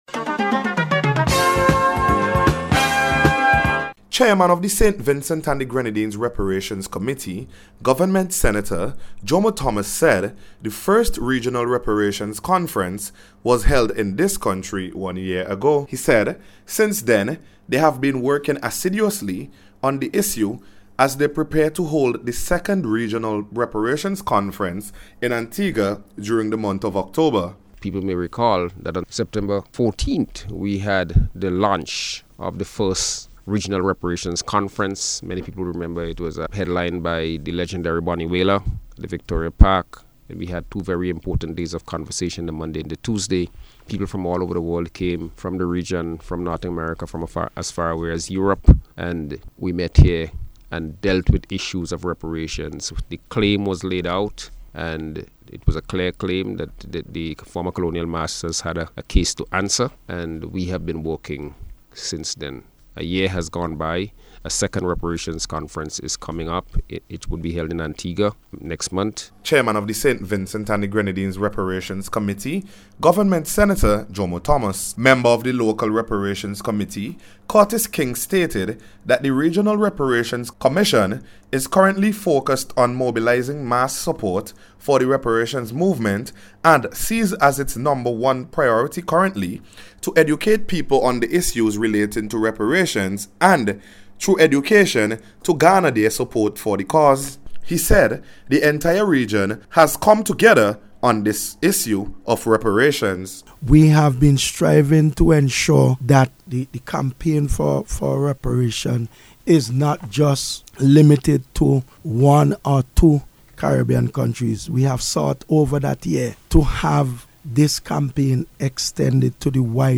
REPARATIONS-CALL-REPORT.mp3